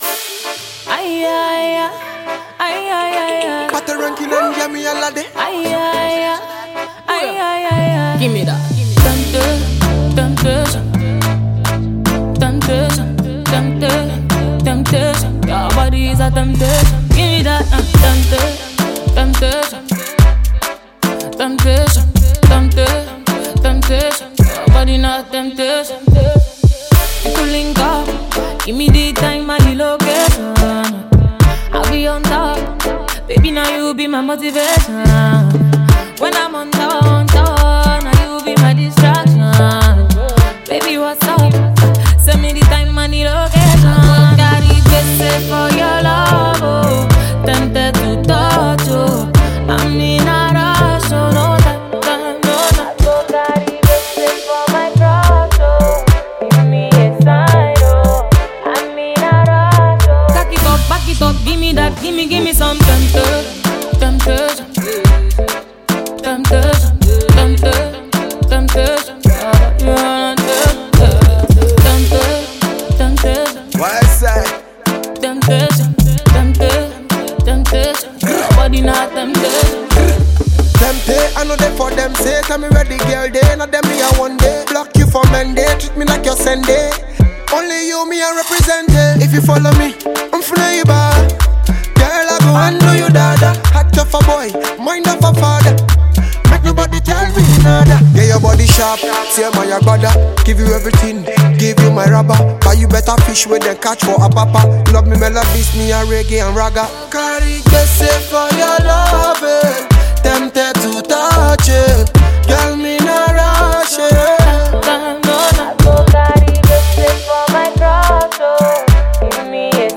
Nigerian Afropop singer and songwriter